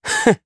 Neraxis-Vox_Happy1_jp.wav